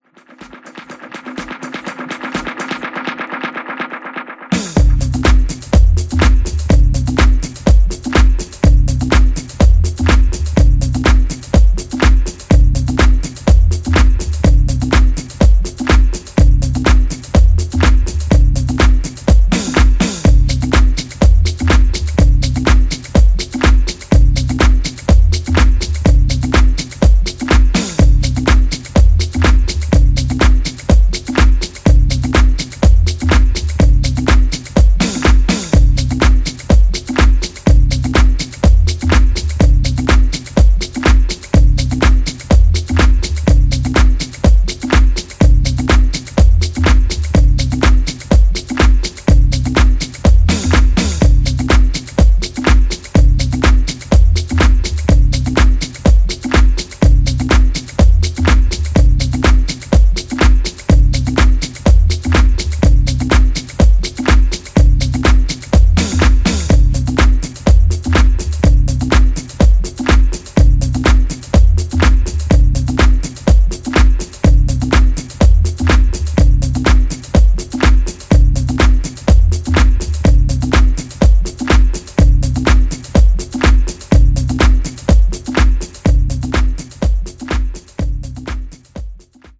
全4トラック共にグレイトな仕上がりで、良質でフロア重視のディープ・ハウスを披露。
ジャンル(スタイル) DEEP HOUSE